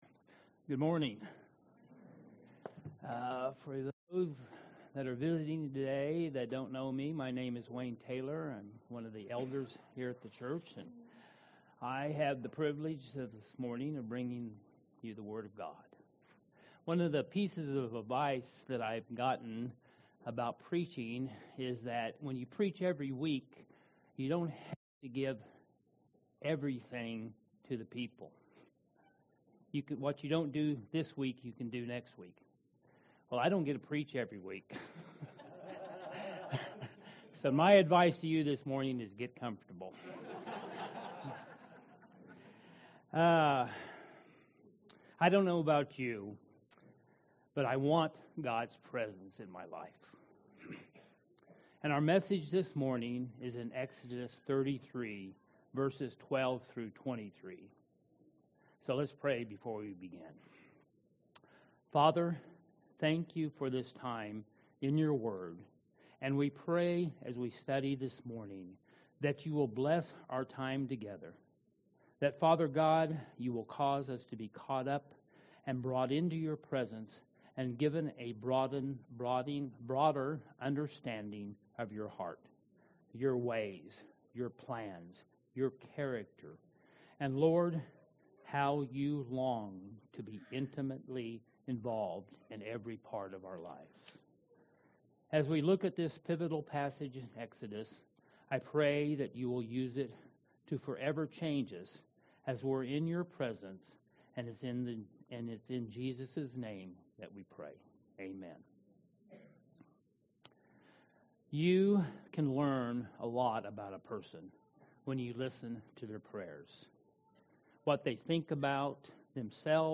Sermons | Vine Community Church
delivers a message from Exodus 33:12-23